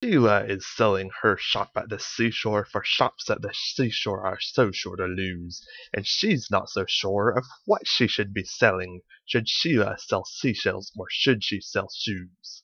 Practice (first part, did it too fast)